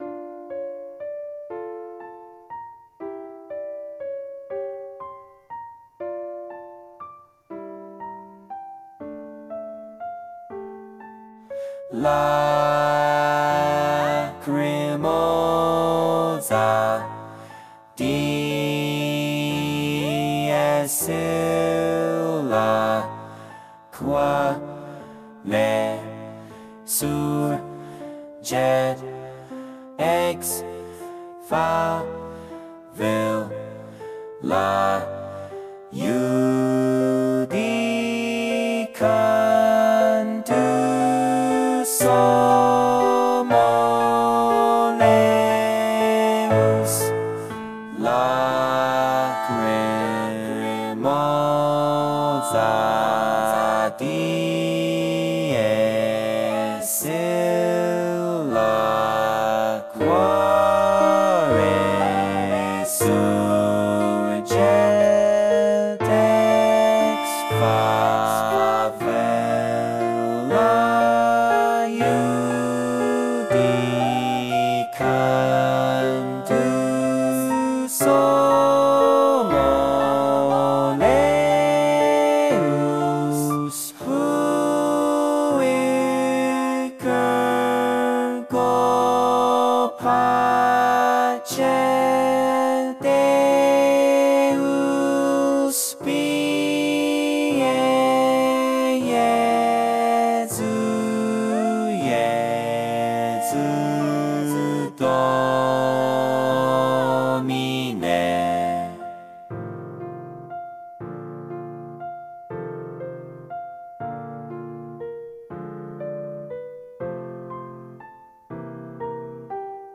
Lacrimosa　音取り音源 LacrimosaSop.mp3　LacrimosaAlt.mp3　LacrimosaTen.mp3